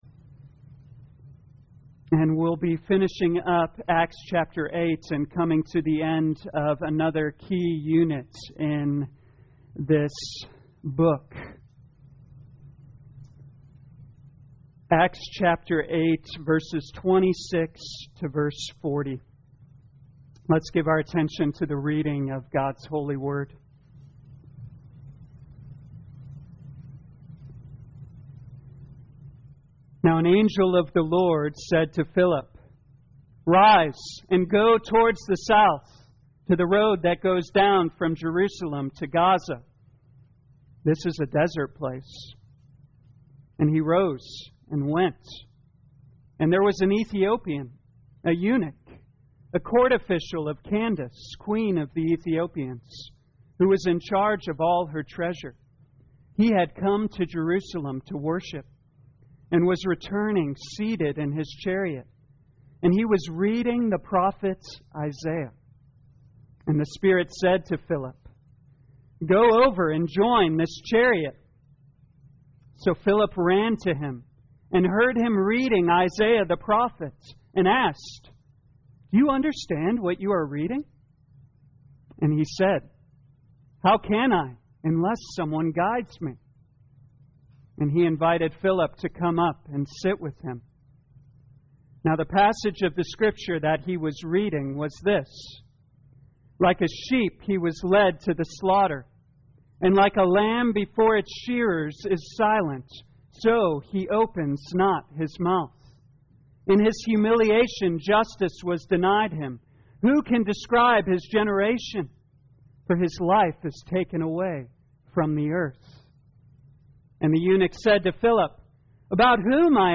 2021 Acts Morning Service Download